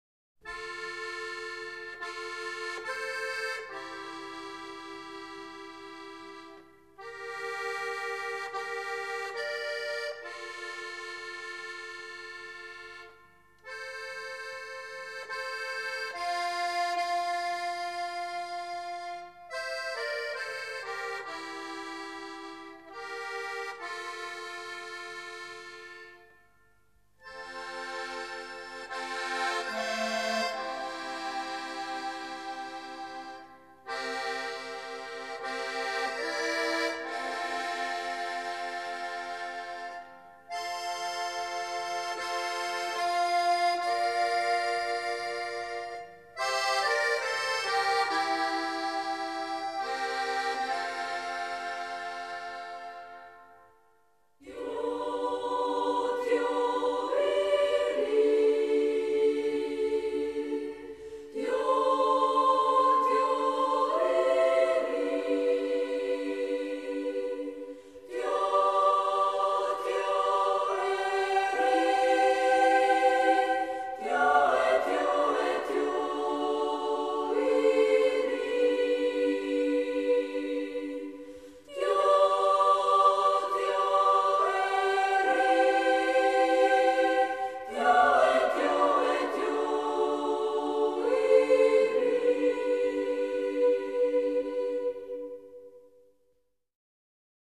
with Orchestra & Choir
The music doesn't hurry.
It was very peaceful to listen to.